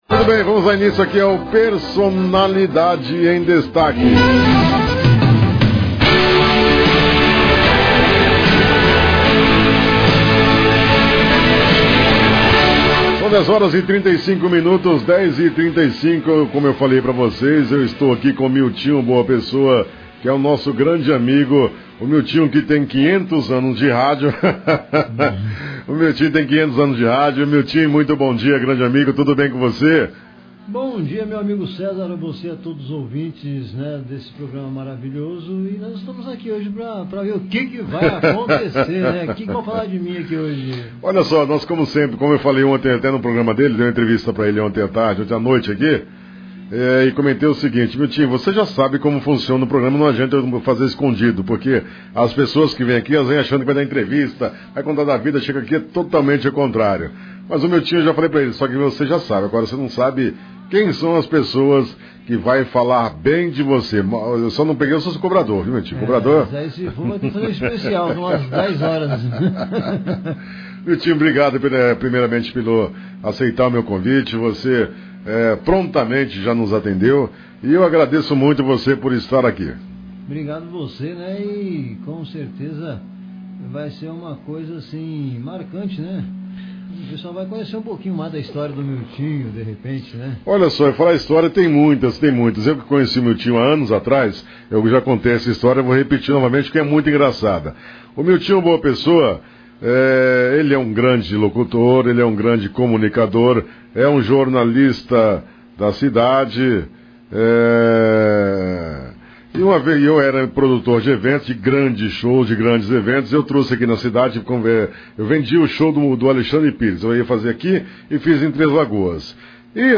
A atração fugiu do formato tradicional de entrevista.